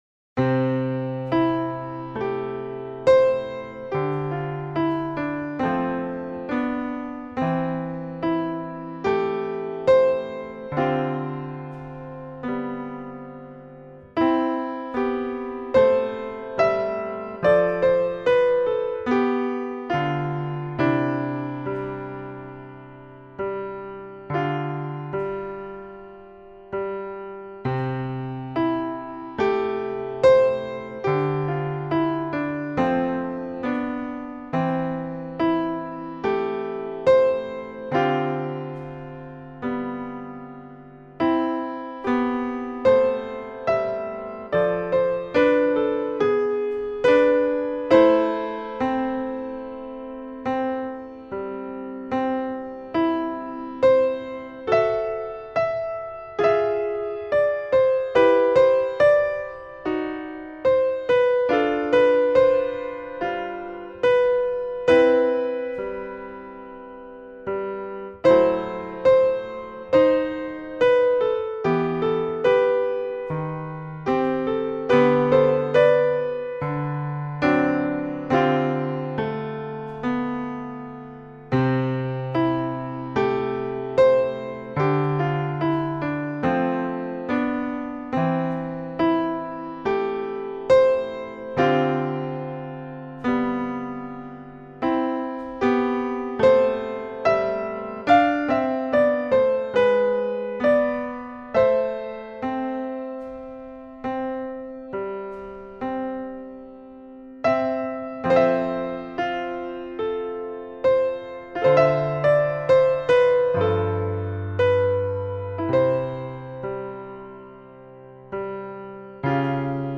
Piano Solo - Late Beginner - Backing tracks